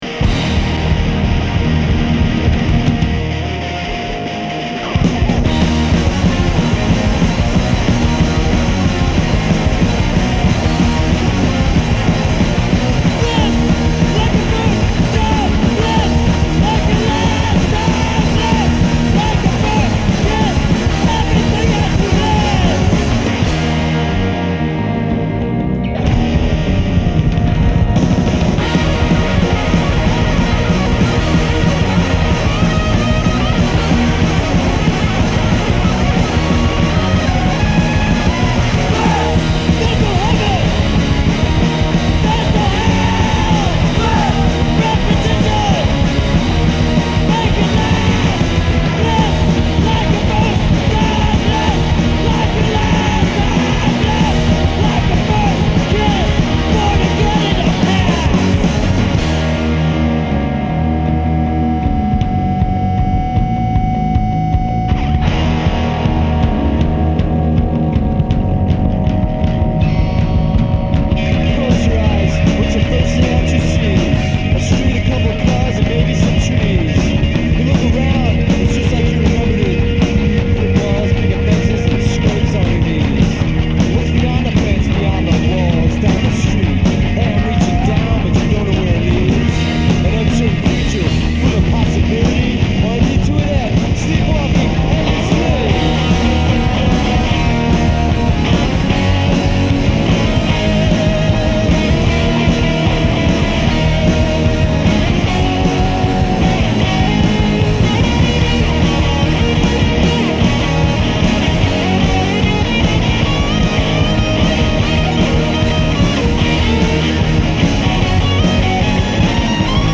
conceptual psychedlic thrash-surf explosion